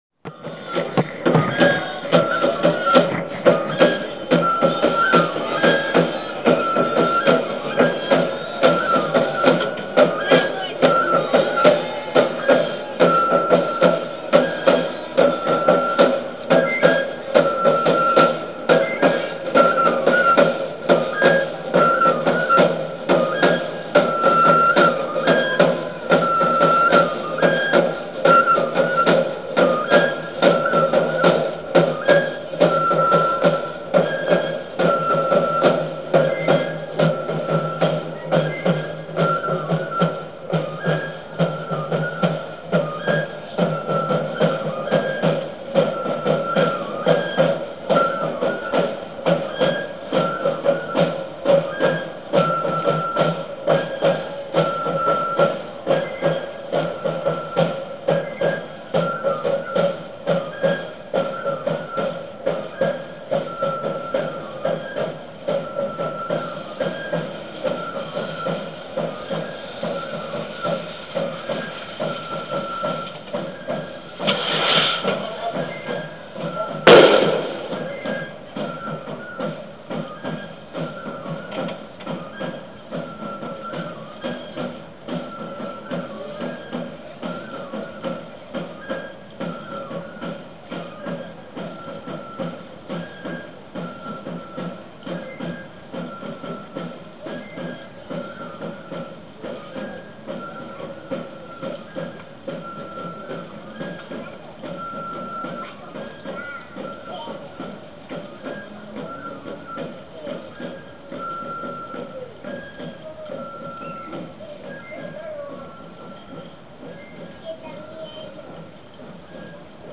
Es una celebración que se realiza en el mes de agosto en el pueblo de Suchiapa, en el audio puede escucharse el tambor y pito junto a estos el aviso con cohetes de carrizo y pólvora.